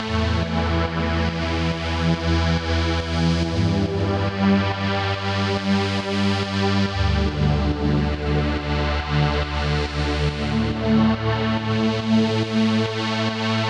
VTS1 Incast Kit 140BPM Deep Pad.wav